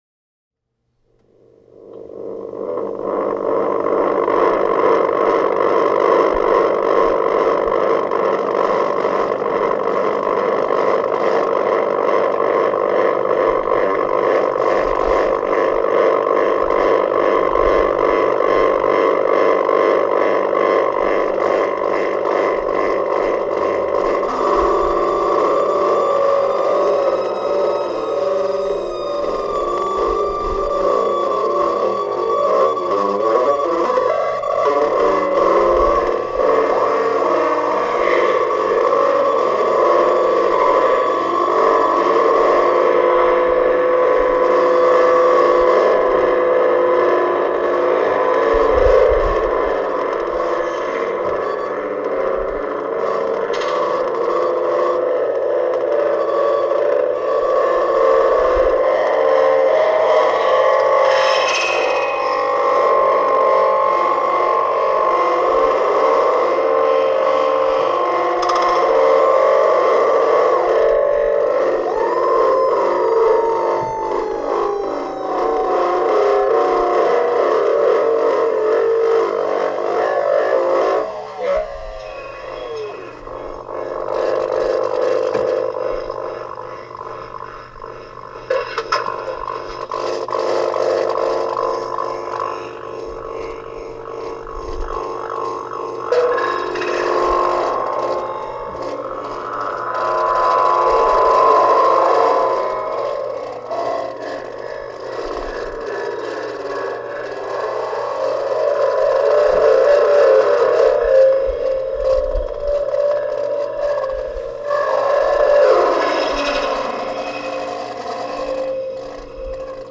Group made instrument:
A network-based instrument with a signal chain that sends a sound source from one place to another in real time, processing the signal at each stage. We chose a mobile telephone as the communication device, sending the signal in this case from one university campus to another (University of Oslo to NTNU).
The setup would eventually pass the sound signal back to its original source; creating wanted or unwanted feedback elements that allowed further experimentation.